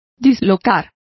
Complete with pronunciation of the translation of dislocate.